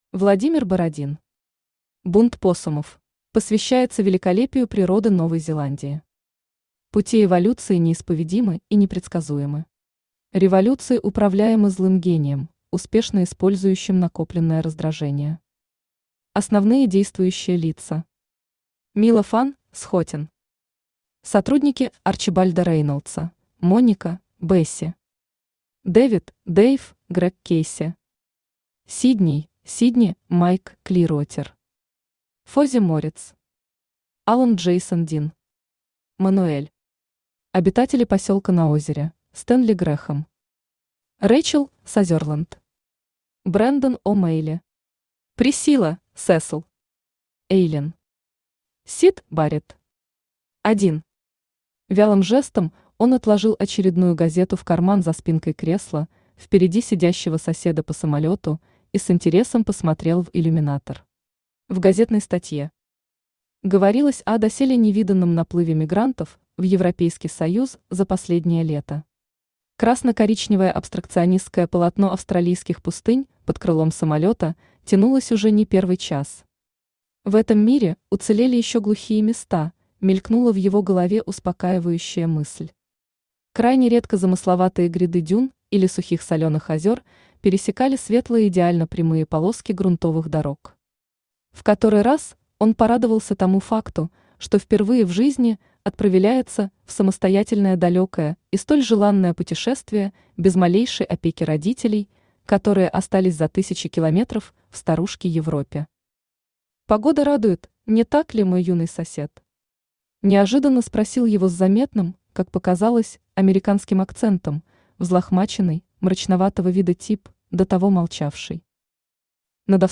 Аудиокнига Бунт поссумов | Библиотека аудиокниг
Aудиокнига Бунт поссумов Автор Владимир Евгеньевич Бородин Читает аудиокнигу Авточтец ЛитРес.